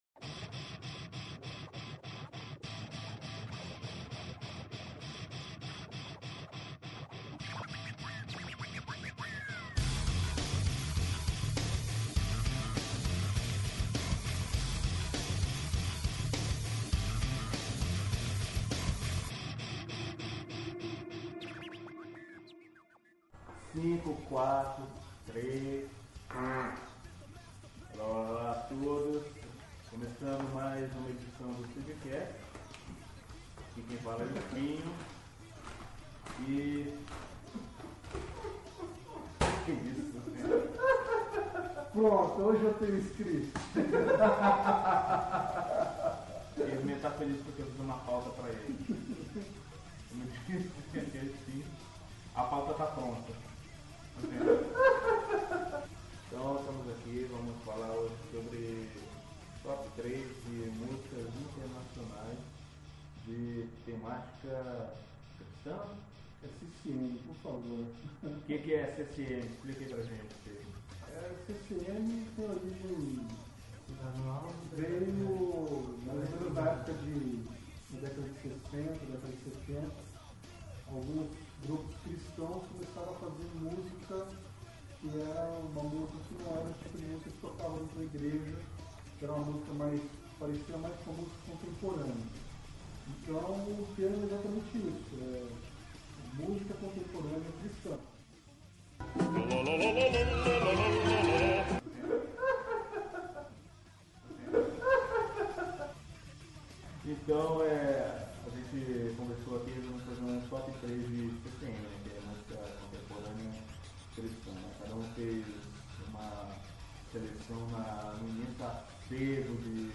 Buscando sempre melhorar e trazer pra vocês uma boa discussão e aprimorar nossa qualidade de gravação nos dedicamos e trazemos um novo padrão, comparado aos nossos programas anteriores e pretendemos melhorar sempre que possivel.